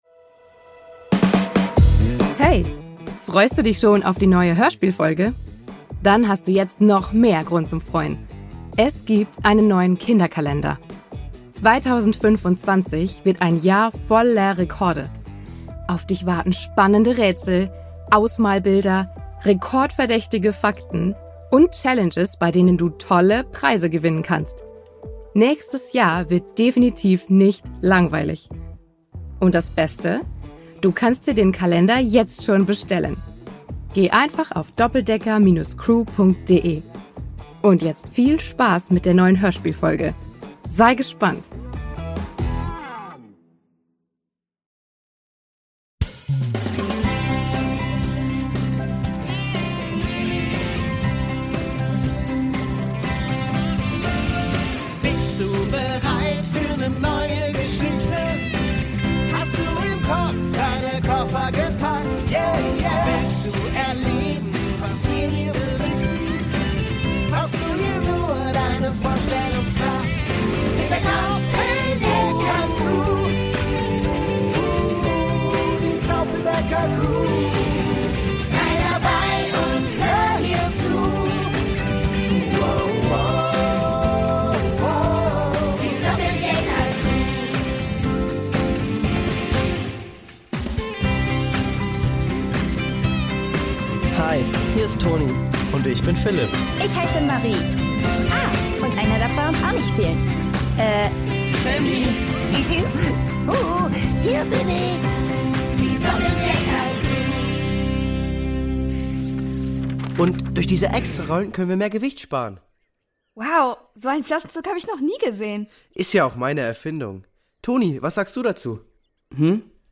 Brasilien 7: Raub im Zoo | Die Doppeldecker Crew | Hörspiel für Kinder (Hörbuch) ~ Die Doppeldecker Crew | Hörspiel für Kinder (Hörbuch) Podcast